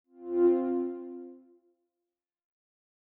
chime.mp3